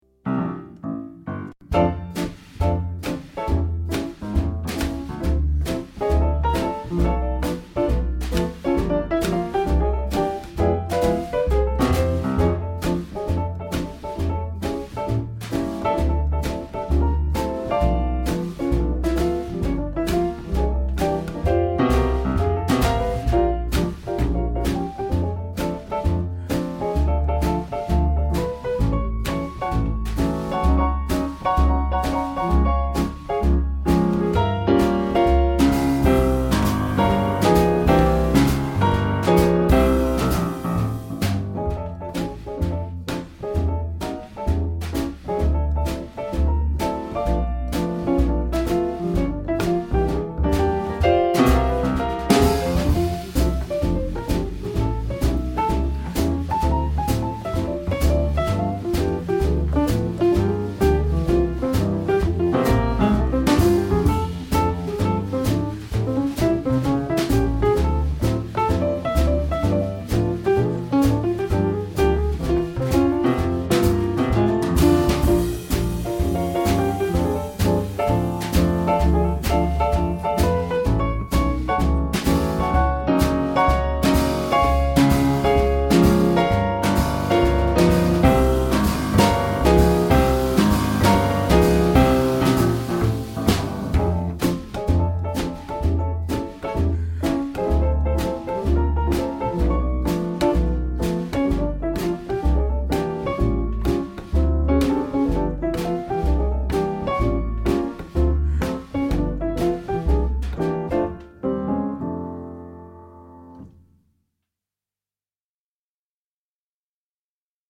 Jazz, Swing Bands
swinging jazz piano trio working in the UK today.
With tight knit, dynamic arrangements, the trio is renowned for its uncanny ability to capture the energy and spirit of the popular piano trios of the 1950’s and 60’s.